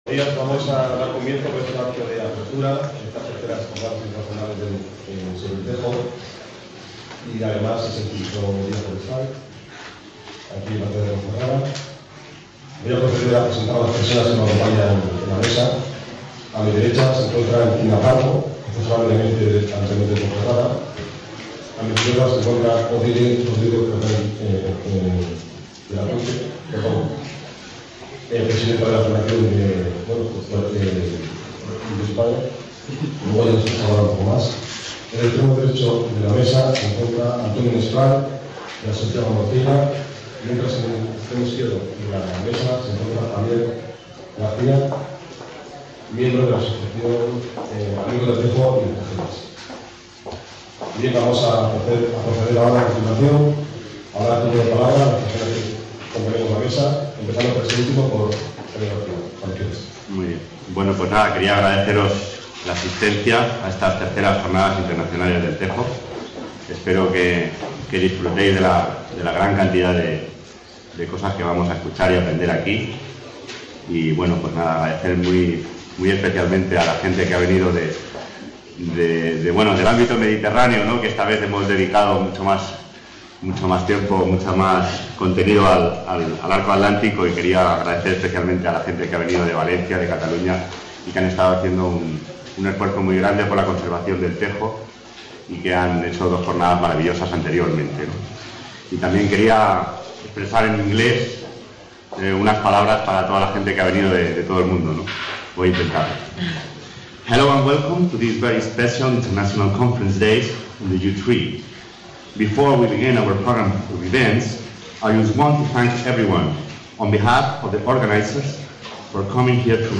Acto apertura - III Jornadas Internacionales del Tejo | Repositorio Digital